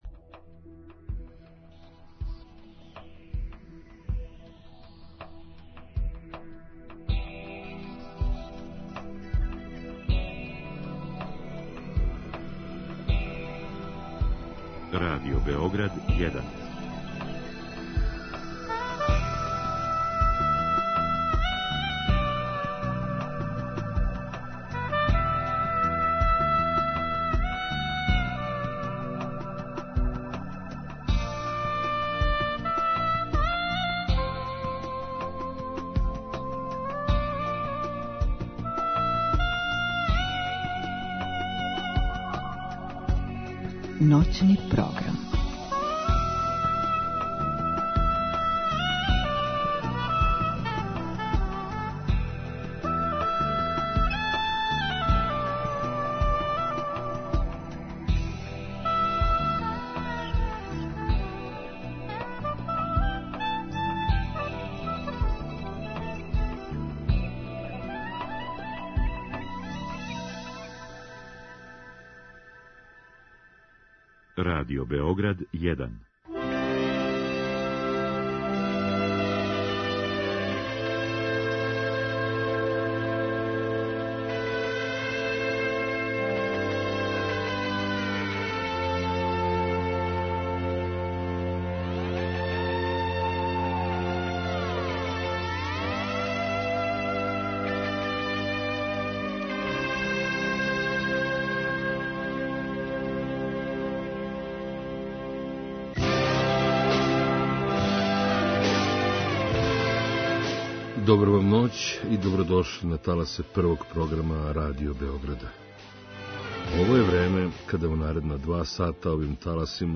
Запловимо заједно таласима Првог програма Радио Београда у ноћи суботе на недељу.